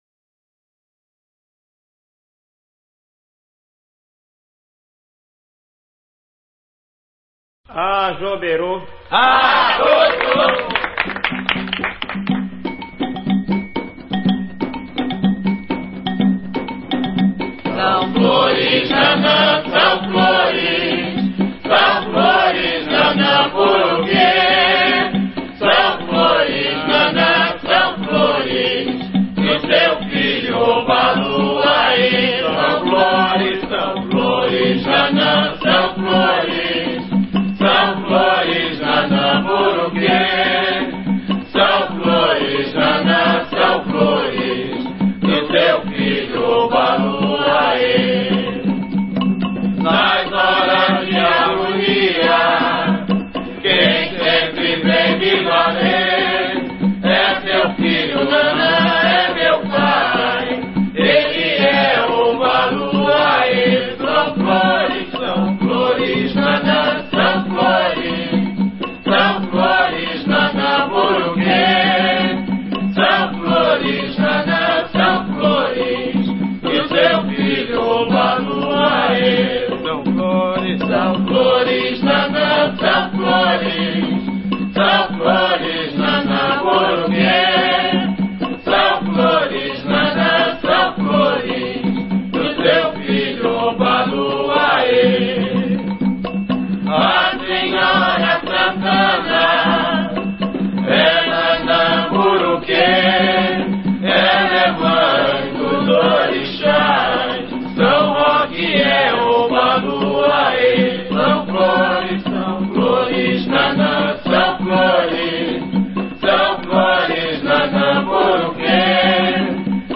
Ensaio Gira – Casa Vó Maria